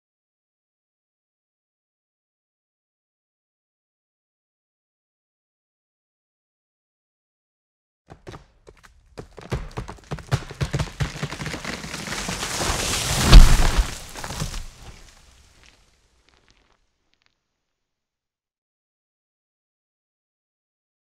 دانلود آهنگ افتادن درخت 3 از افکت صوتی طبیعت و محیط
دانلود صدای افتادن درخت 3 از ساعد نیوز با لینک مستقیم و کیفیت بالا
جلوه های صوتی